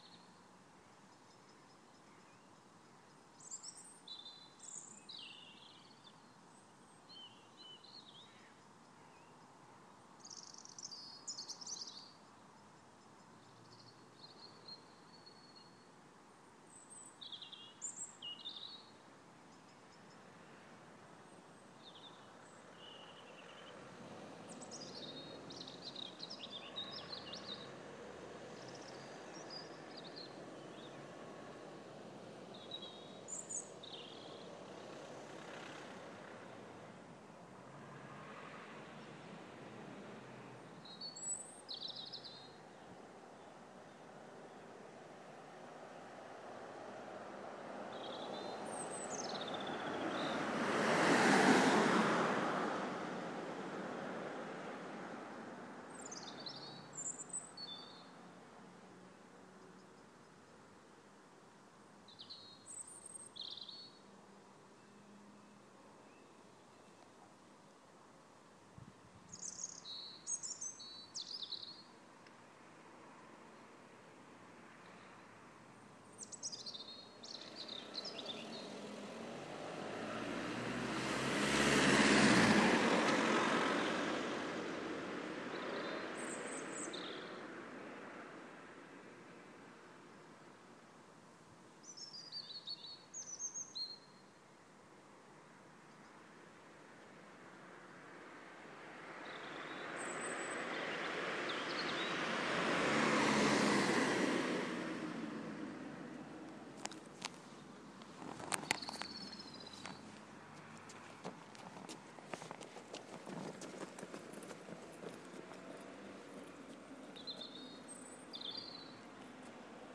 Evening birdsong Boo